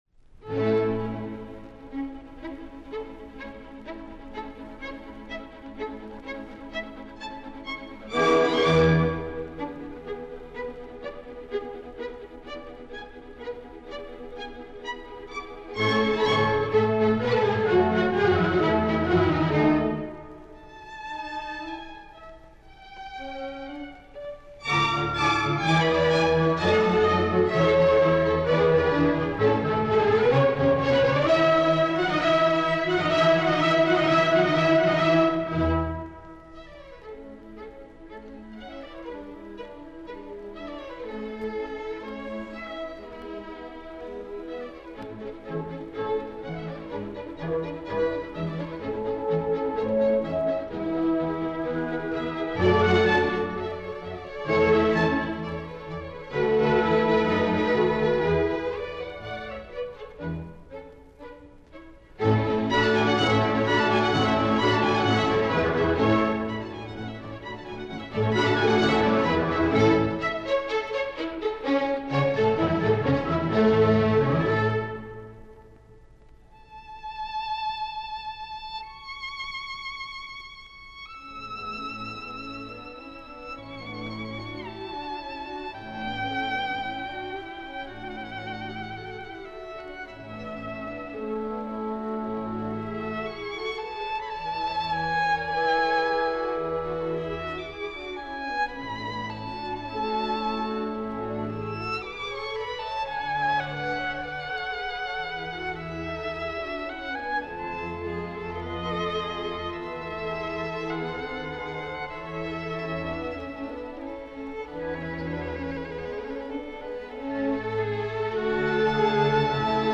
The violin is very much a part of the orchestra, rather than sitting aside from it.
In the opening movement, the orchestra presents most of the themes, and the violin pushes this by introducing yet another theme.
Wolfgang Amadeus Mozart: Concerto for Violin and Orchestra No. 5 in A Major, K. 219 – I. Allegro Aperto-Adagio-Allegro Aperto
This recording was made in 1949 with violinist David Oistrakh performing with Nikolai Golovanov leading the Radio Symphony Orchestra of the USSR.
David Oistrakh
Radio Symphony Orchestra of the USSR